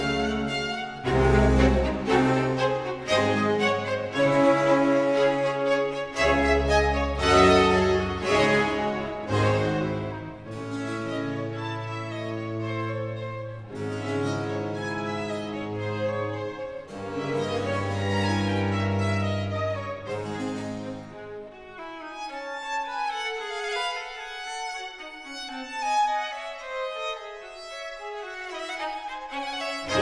"enPreferredTerm" => "Musique orchestrale"
"enPreferredTerm" => "Musique de chambre"